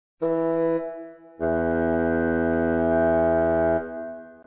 • il primo è più breve;
• il secondo è più lungo.